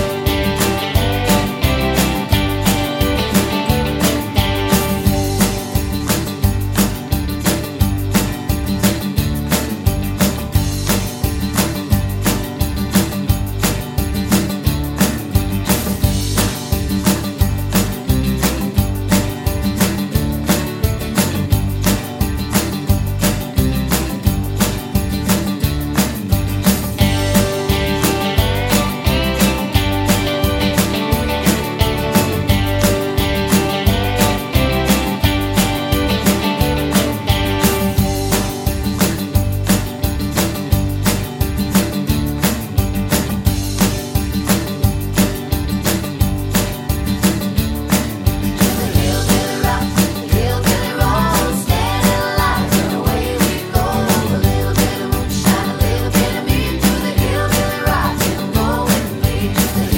no Backing Vocals Country (Male) 2:35 Buy £1.50